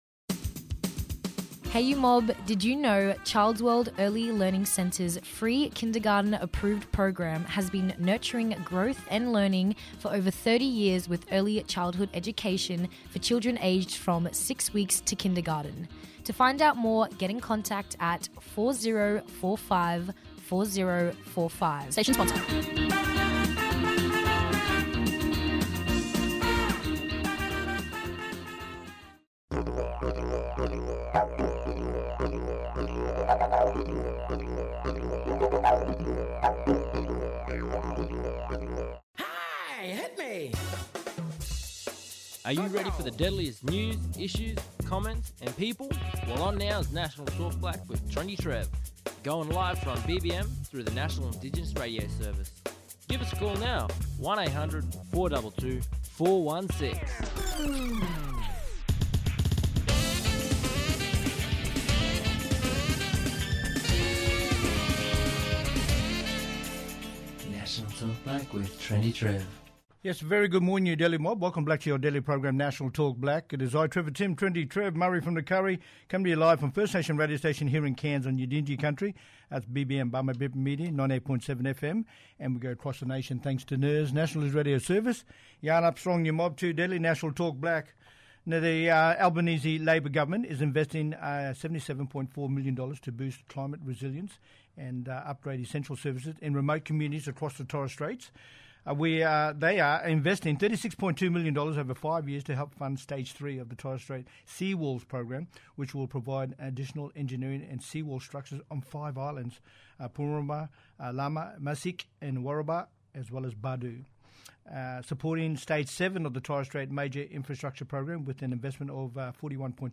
Senator Malarndirri McCarthy – Northern Territory, Minister for Indigenous Australians, talking about supporting climate resilience in the Torres Strait.